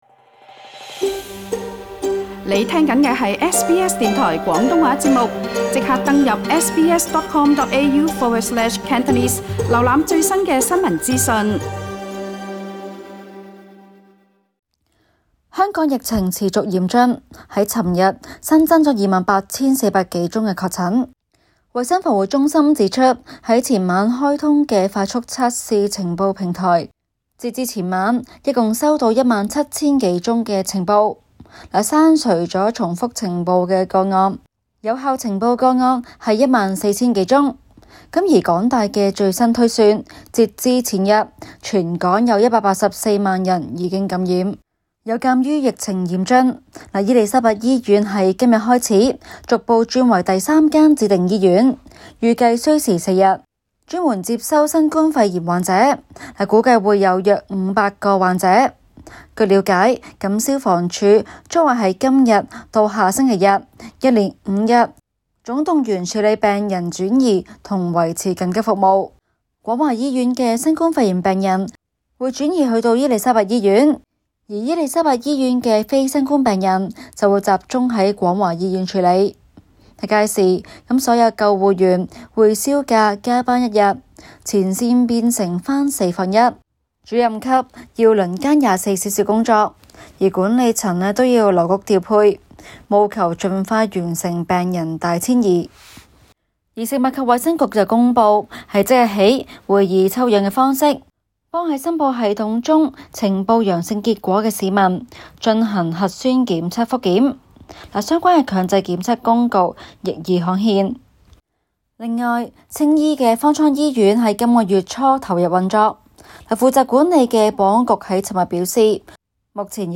中港快訊